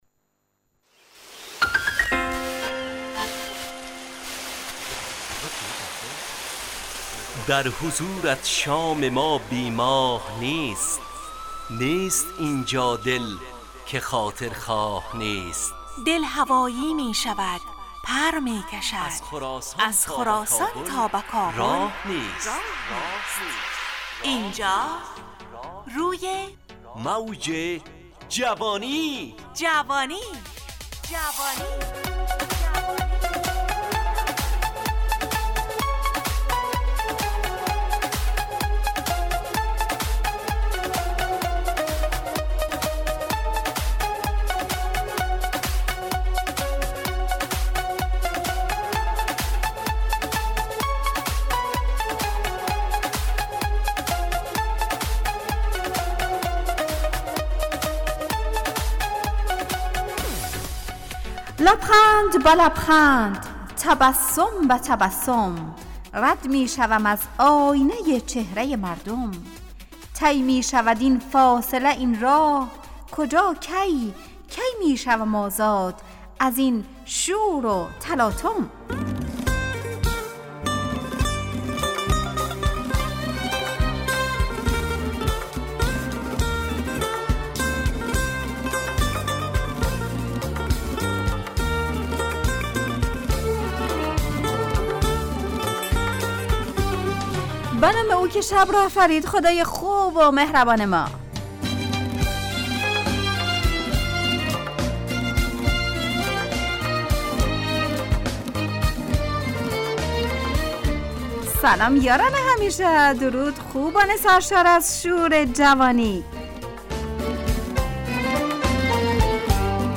همراه با ترانه و موسیقی مدت برنامه 55 دقیقه .